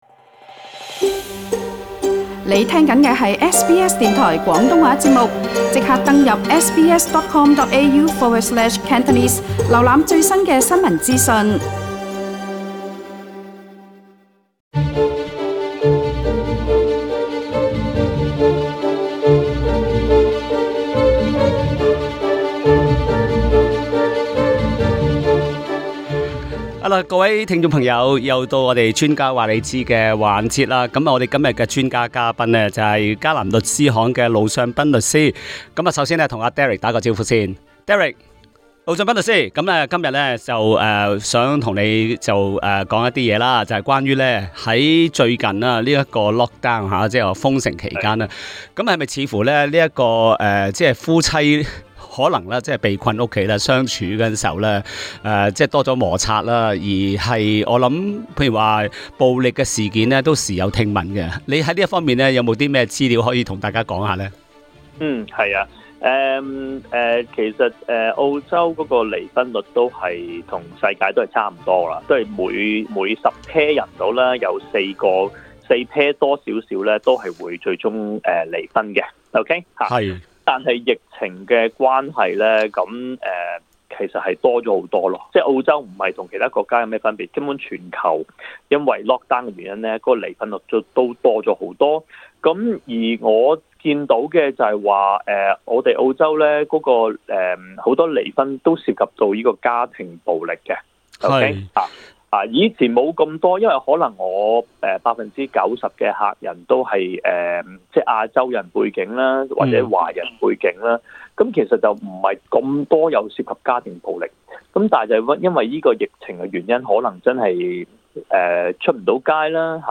他還解答了聽眾提問，包括遺囑問題。